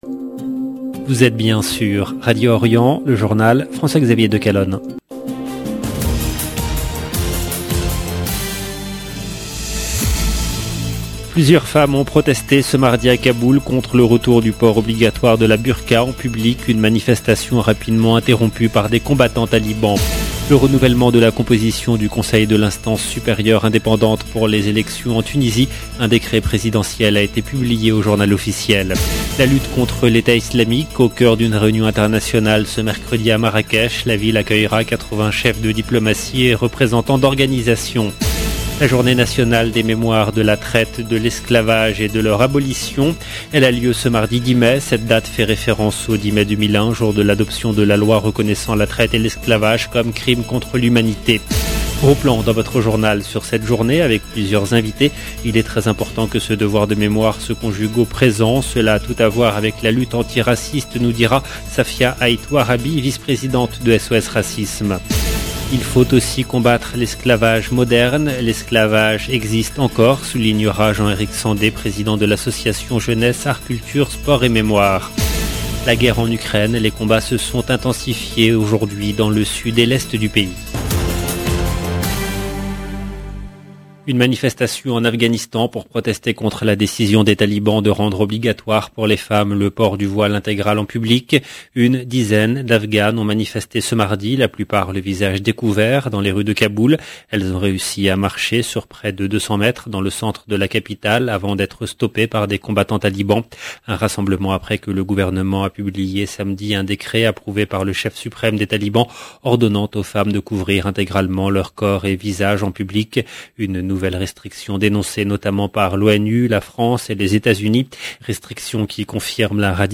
LB JOURNAL EN LANGUE FRANÇAISE
Gros plan dans votre journal sur cette journée avec plusieurs invités.